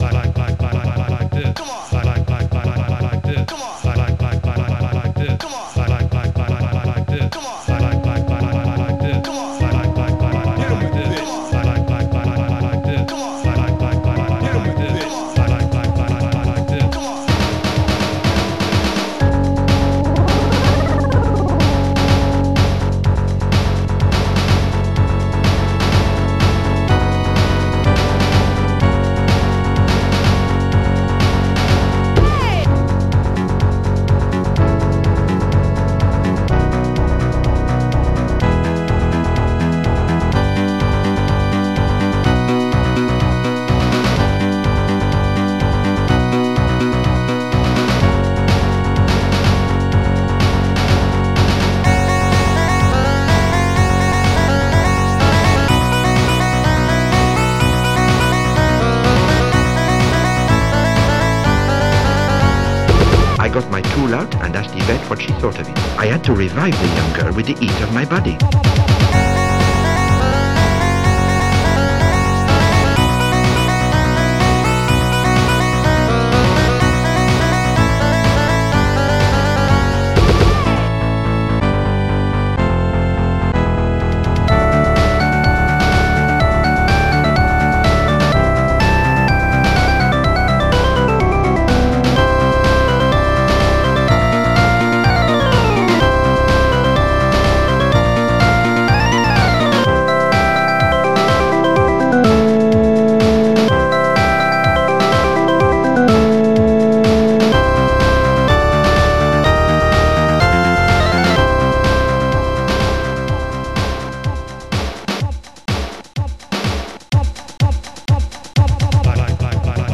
Star Tracker/StarTrekker Module  |  1991-08-29  |  230KB  |  2 channels  |  44,100 sample rate  |  2 minutes, 10 seconds
Protracker and family